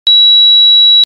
Buzzers Transducer, Externally Driven Piezo 3V 9mA 3.5kHz 90dB @ 3V, 30cm Through Hole PC Pins
Sound Pressure Level (dB)90
Frequency (Hz)3900
Sine-3900Hz-Audio-Only.mp3